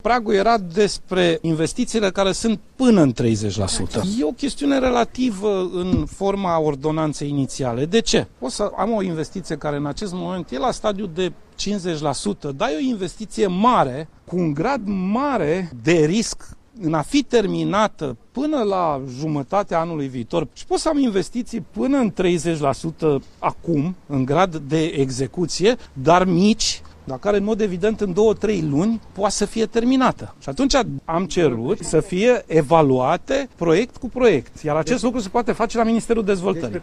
Preşedintele interimar al PSD, Sorin Grindeanu spune că întelege necesitatea Ordonanţei de Urgenţă a Guvernului privind investiţiile derulate prin PNRR, dar atrage atenţia că fiecare proiect semnat trebuie analizat obiectiv: